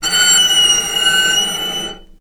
vc_sp-F#6-ff.AIF